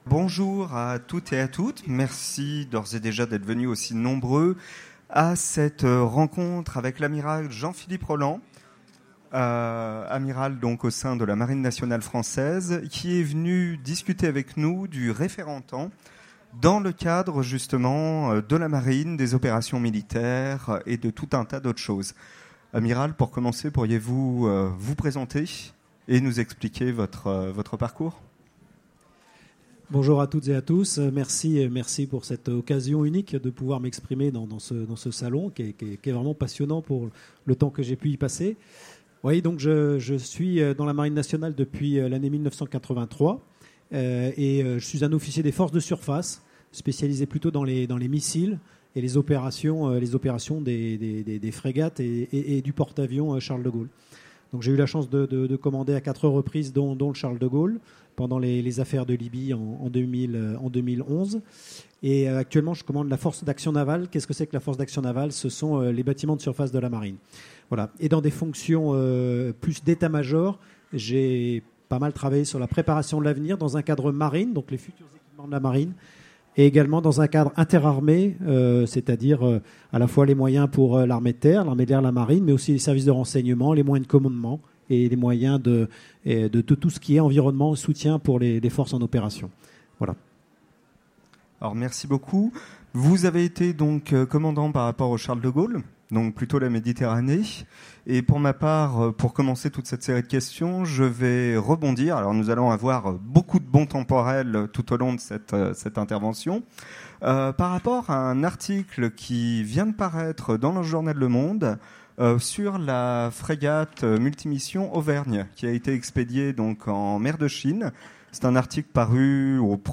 Utopiales 2017 : Rencontre avec le VAE Jean-Philippe Rolland, amiral commandant la FAN Télécharger le MP3 à lire aussi Jean-Philippe Rolland Genres / Mots-clés Militaire Conférence Partager cet article